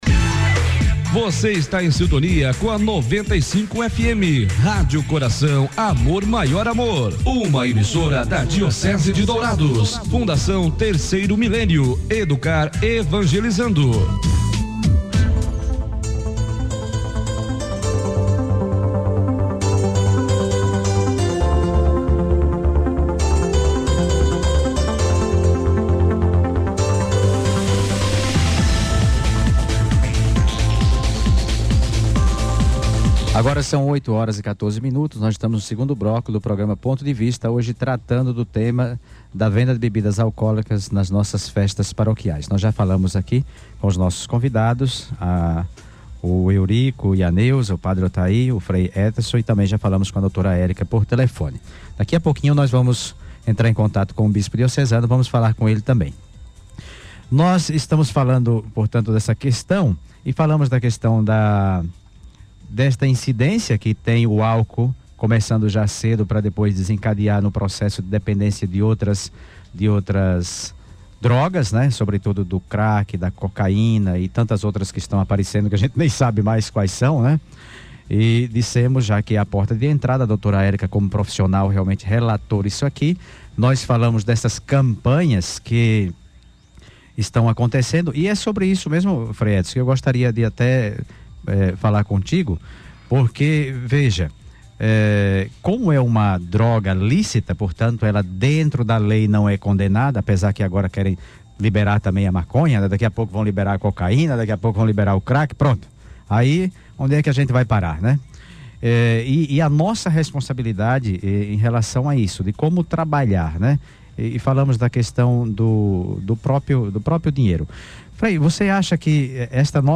Participação por telefone Por telefone